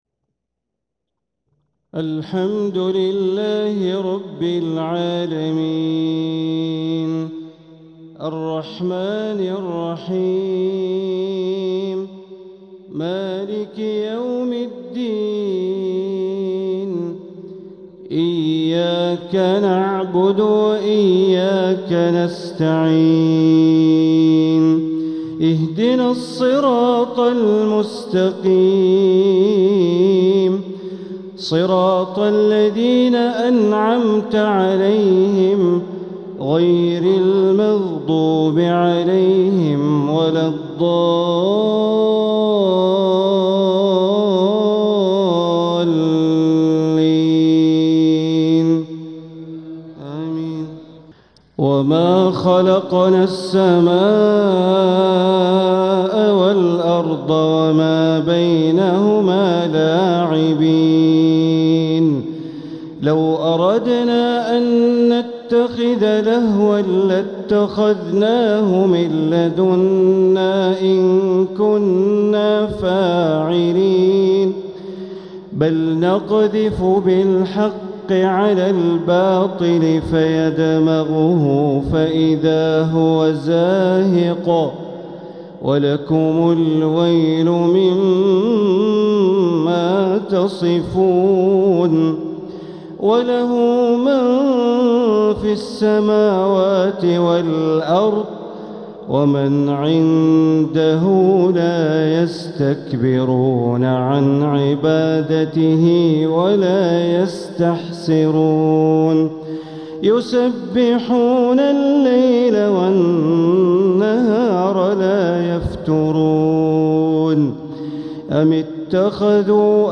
تلاوة من سورة الأنبياء ١٦-٢٩ | عشاء الأحد ٢٩ربيع الأول١٤٤٧ > 1447هـ > الفروض - تلاوات بندر بليلة